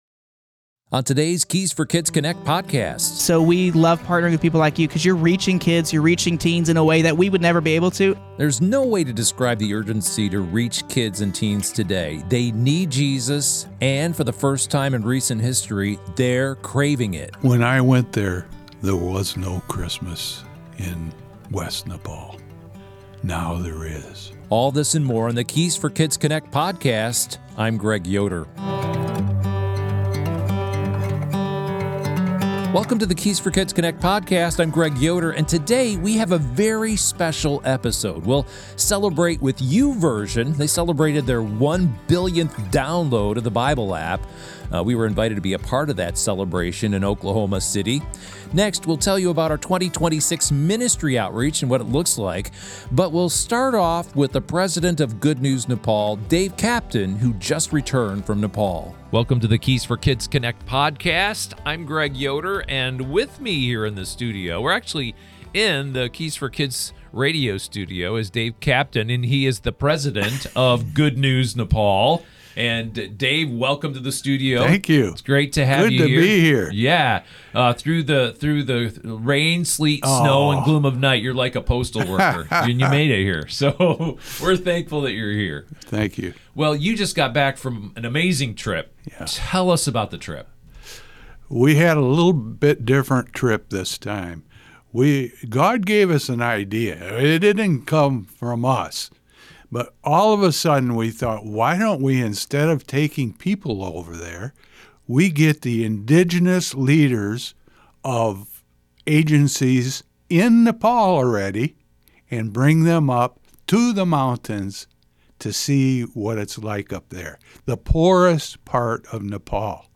sits down in the Keys for Kids Radio studio